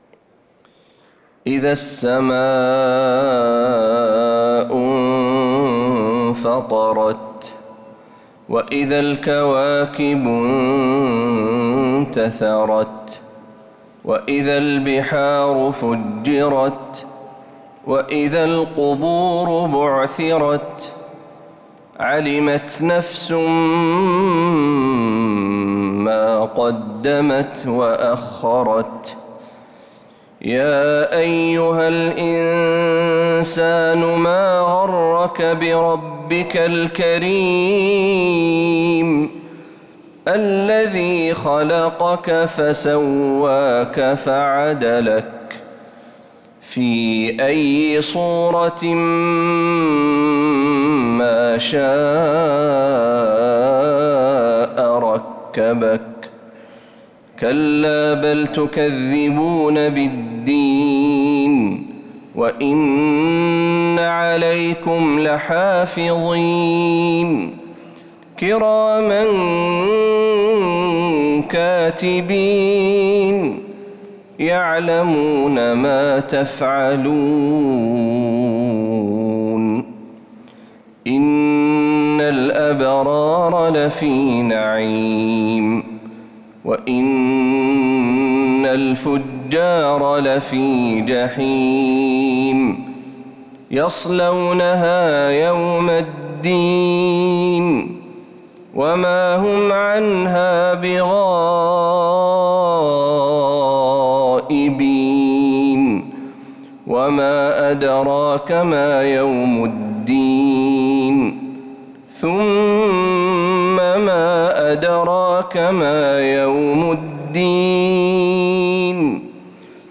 سورة الإنفطار كاملة من فجريات الحرم النبوي للشيخ محمد برهجي | رجب 1446هـ > السور المكتملة للشيخ محمد برهجي من الحرم النبوي 🕌 > السور المكتملة 🕌 > المزيد - تلاوات الحرمين